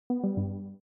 left_call-BbqmRgnC.mp3